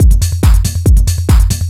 DS 140-BPM A2.wav